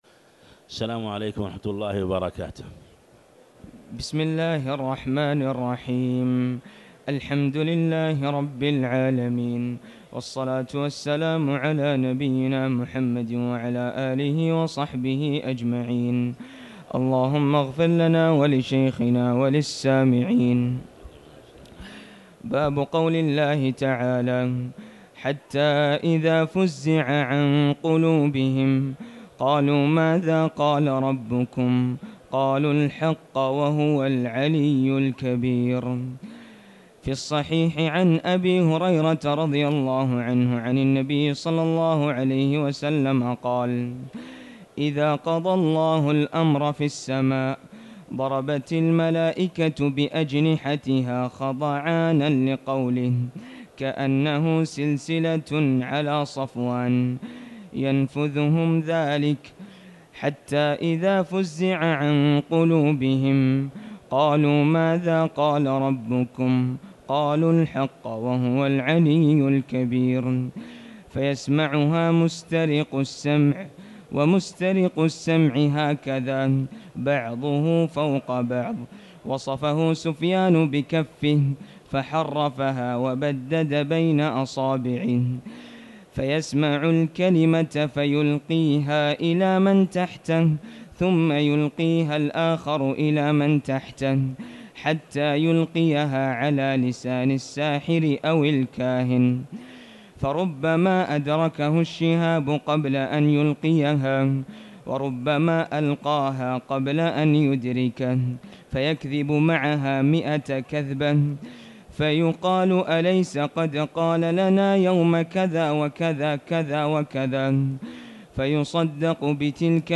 تاريخ النشر ١٨ رمضان ١٤٤٠ هـ المكان: المسجد الحرام الشيخ